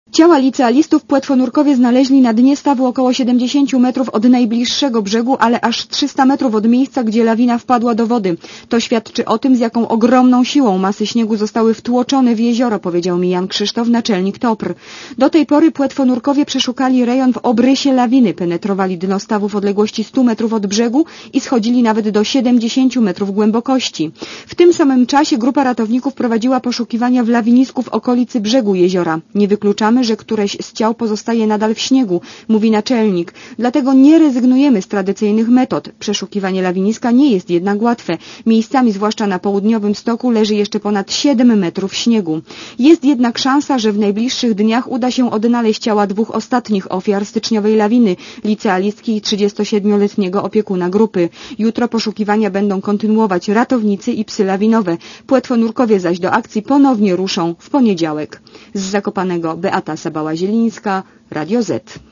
(RadioZet) Źródło: (RadioZet) Komentarz audio (244Kb) Około godz. 18 poszukiwania przerwano.